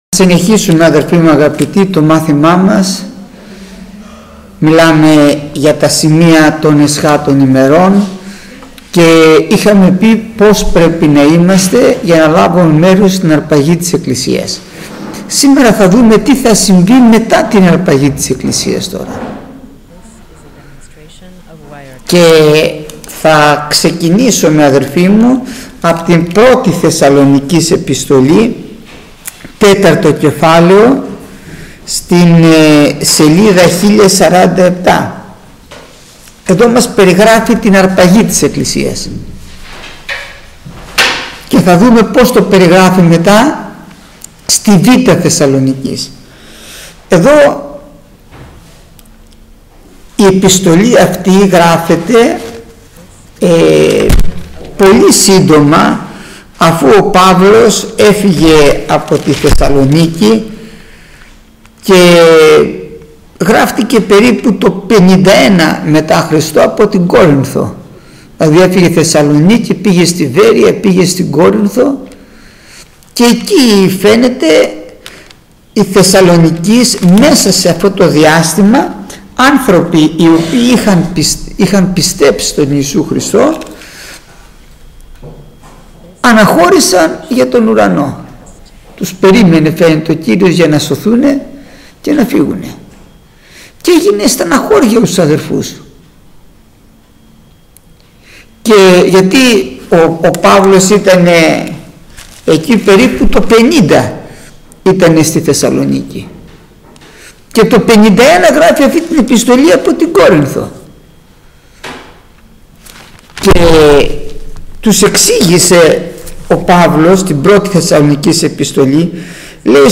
Γεννηθήτω το θέλημά σου (τα σημεία των εσχάτων ημερών) – Μάθημα 109o – Ελευθέρα Αποστολική Εκκλησία Πεντηκοστής Νάξου